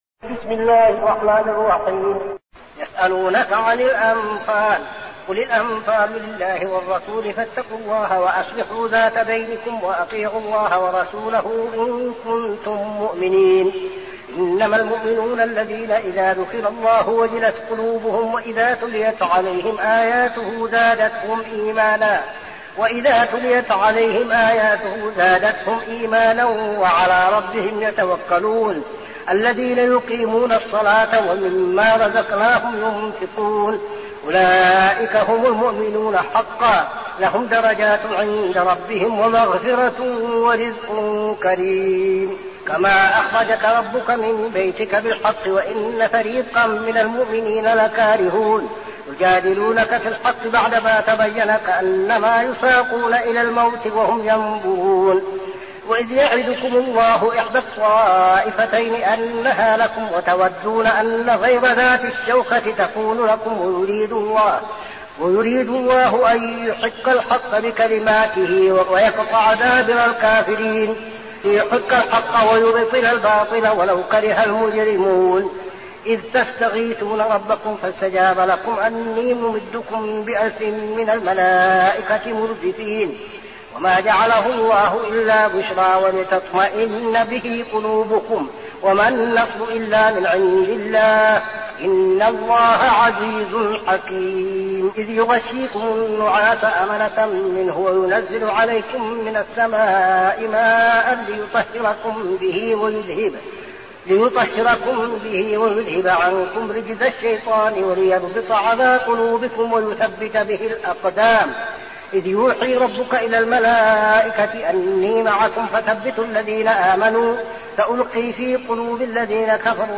المكان: المسجد النبوي الشيخ: فضيلة الشيخ عبدالعزيز بن صالح فضيلة الشيخ عبدالعزيز بن صالح الأنفال